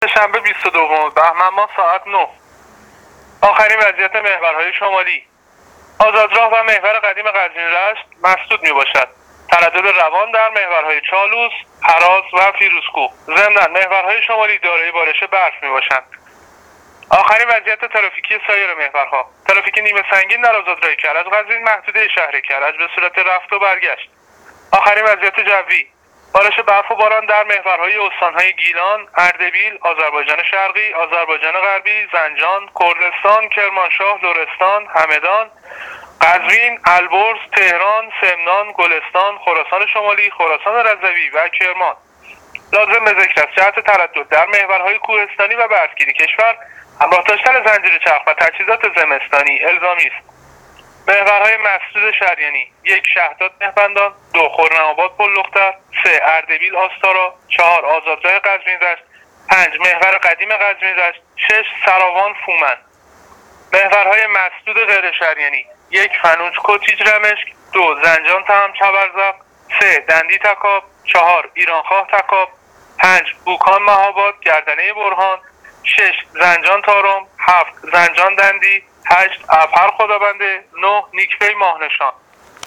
گزارش رادیو اینترنتی از آخرین وضعیت ترافیکی جاده‌ها تا ساعت ۹سه شنبه ۲۲ بهمن‌ماه ۱۳۹۸